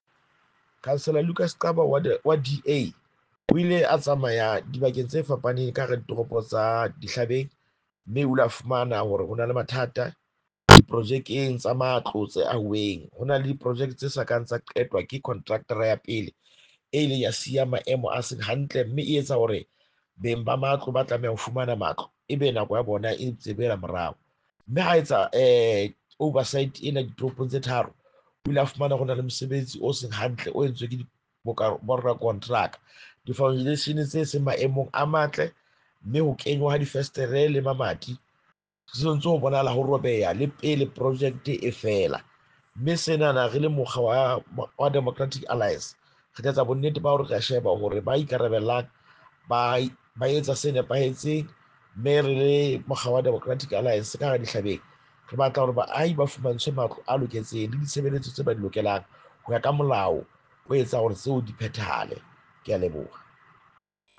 Sesotho soundbites by Cllr Lucas Xaba and